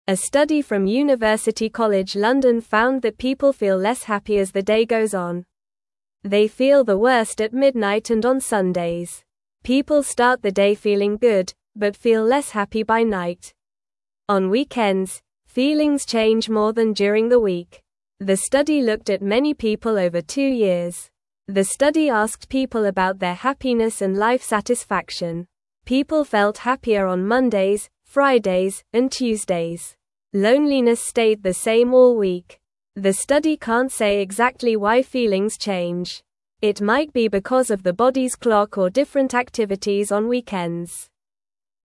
Normal
English-Newsroom-Beginner-NORMAL-Reading-Why-People-Feel-Happier-at-the-Start-of-Days.mp3